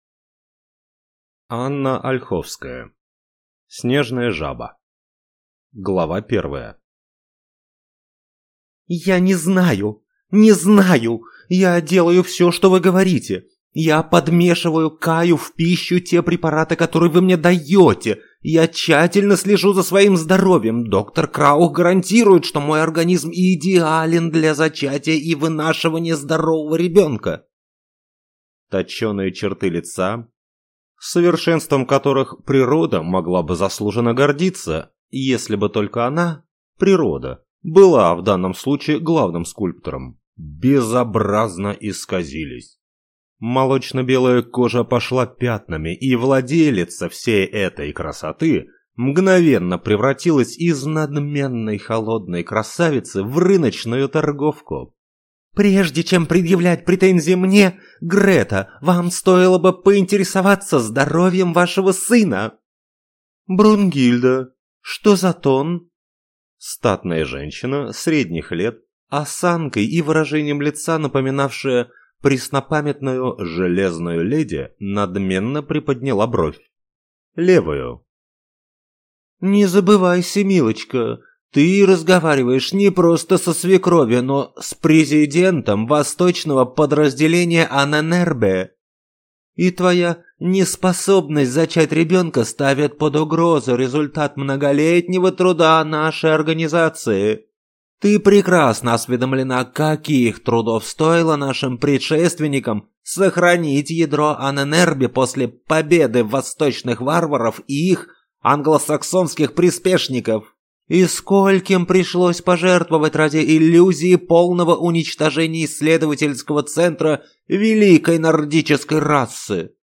Аудиокнига Снежная жаба | Библиотека аудиокниг